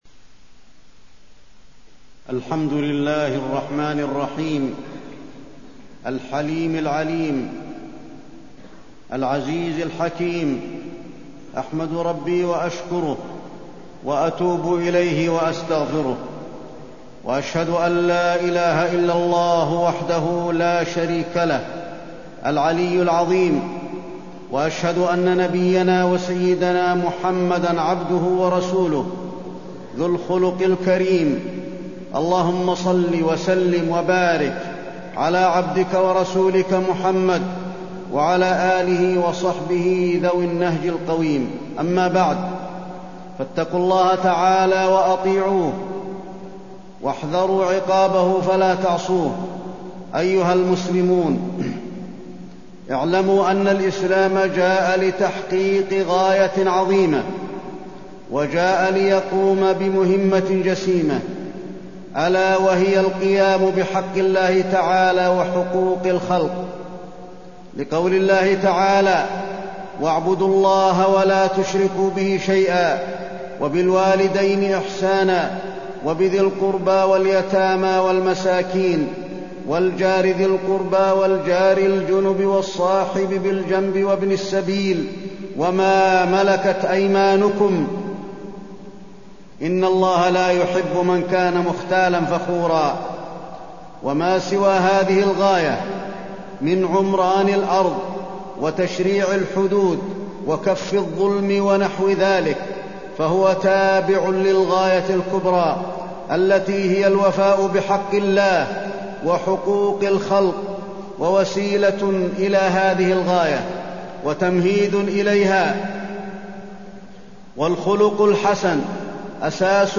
تاريخ النشر ٢٤ ربيع الثاني ١٤٢٣ هـ المكان: المسجد النبوي الشيخ: فضيلة الشيخ د. علي بن عبدالرحمن الحذيفي فضيلة الشيخ د. علي بن عبدالرحمن الحذيفي حسن الخلق